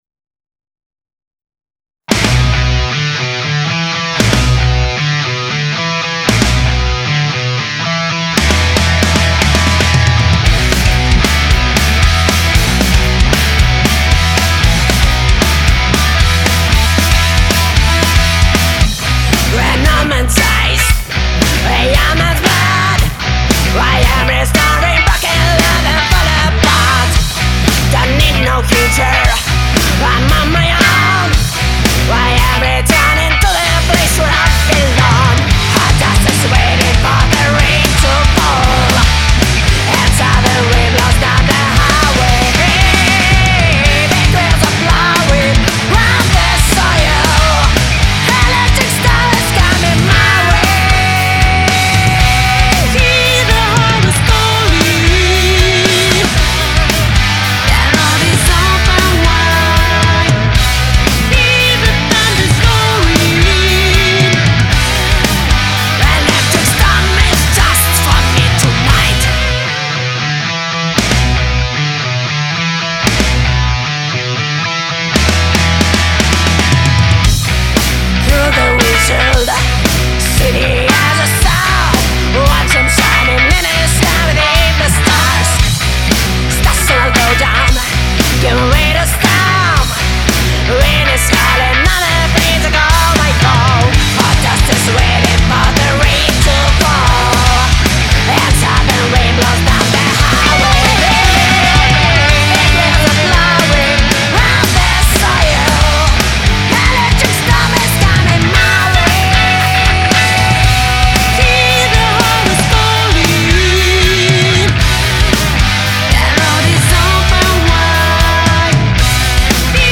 соло и чистый звук - софт (позже доделаетсся)
ну и звук так и задумывался олдскульным
а зачем так середину в гитарах провалил?  :crazy: